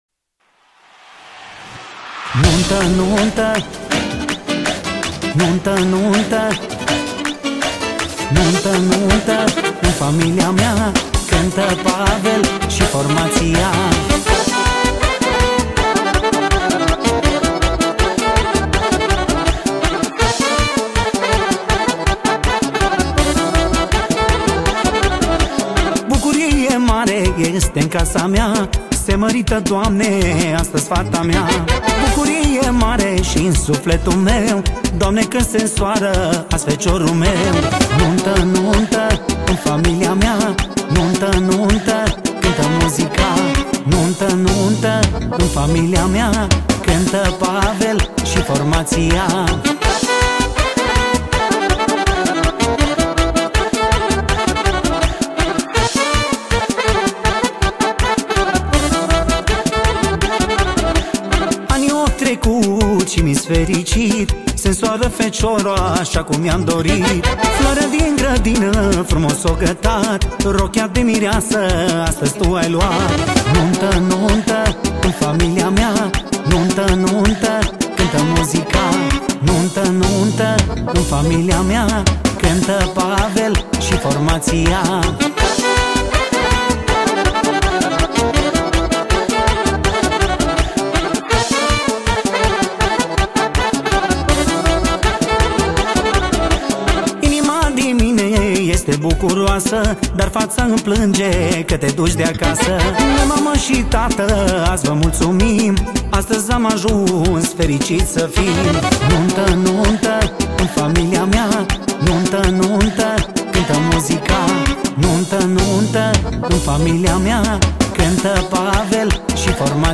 Categoria: Petrecere New